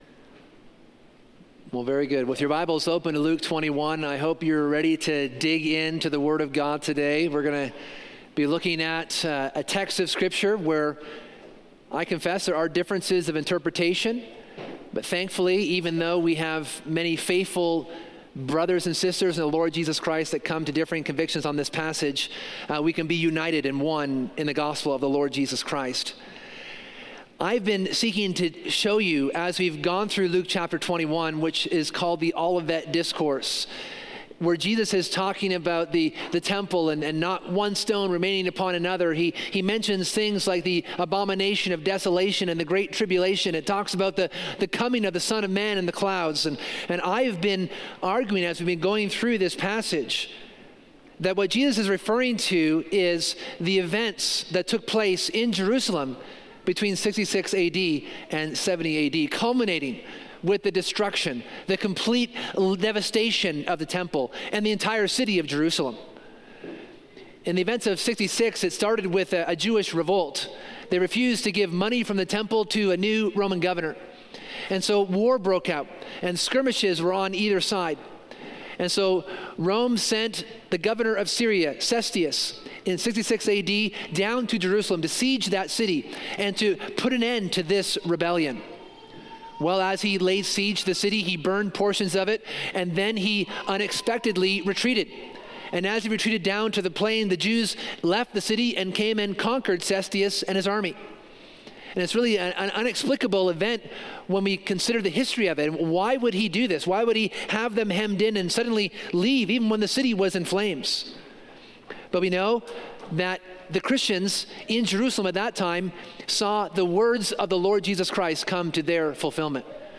This sermon considers the “coming of the Son of Man” in the destruction of the temple in 70AD. Jesus uses prophetic and apocalyptic language to describe the coming judgment of Jerusalem that is orchestrated by his own hand. Jesus removes the kingdom from the Jews who rejected him and grants it to all who call upon his name.